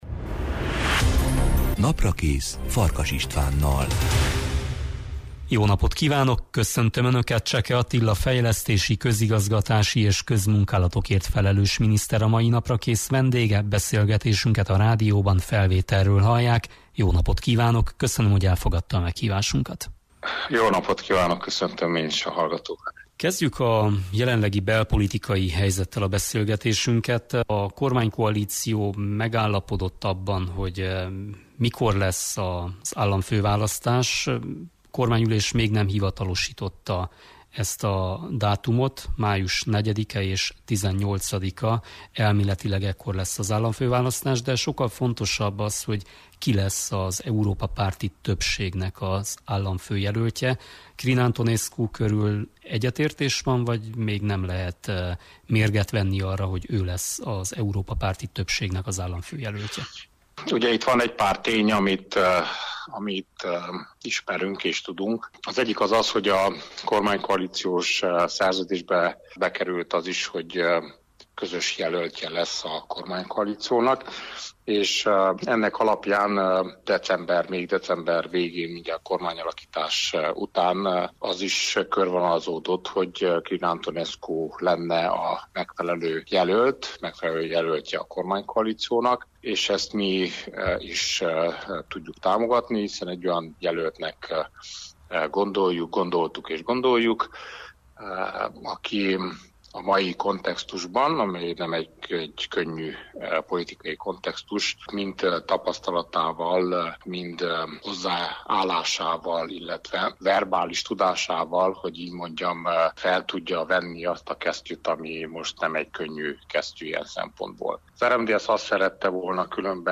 Szó volt az eddigi kihívásokról, prioritásokról, és arról is, mit kell tenni, hogy ne álljanak le a beruházások az országban. Beszélgettünk a pénzügyi akadályokról, az építkezések lassúságáról, és arról, hogyan látja Románia fejlődését a következő évtizedekben.